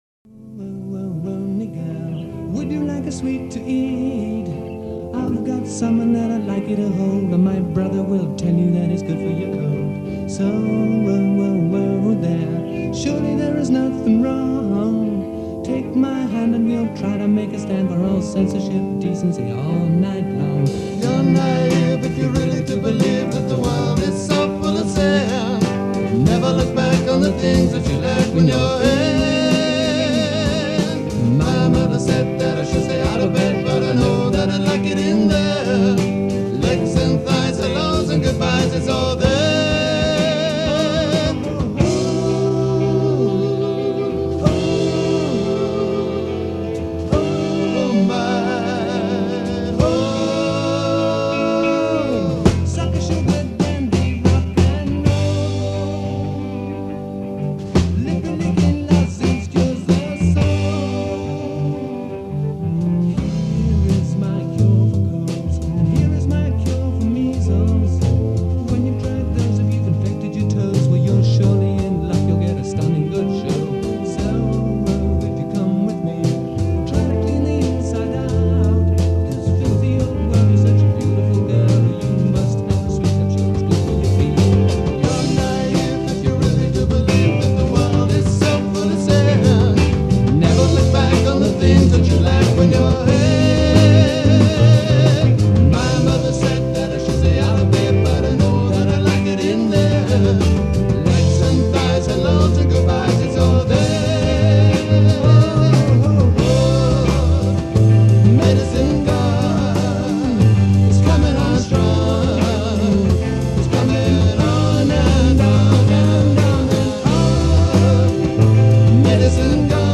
Legends of Progressive Rock In the 70s.